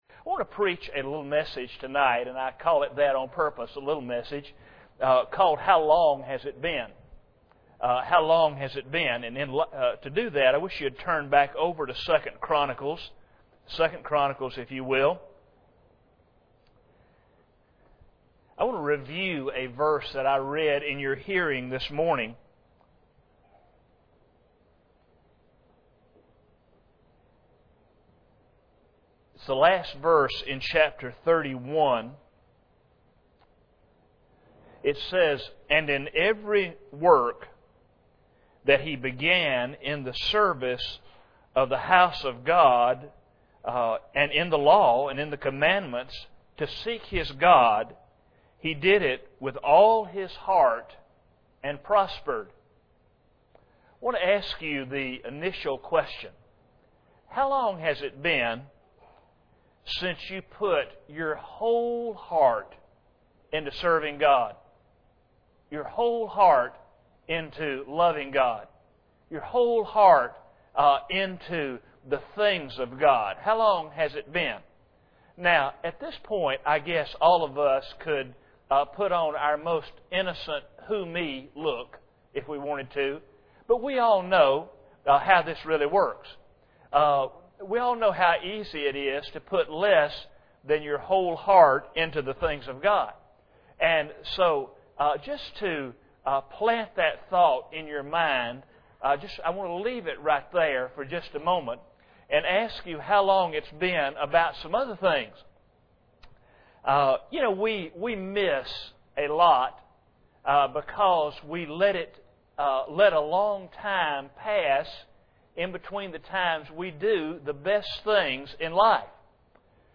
2 Chronicles 31:21 Service Type: Sunday Evening Bible Text